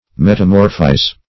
Metamorphize \Met`a*mor"phize\, v. t. To metamorphose.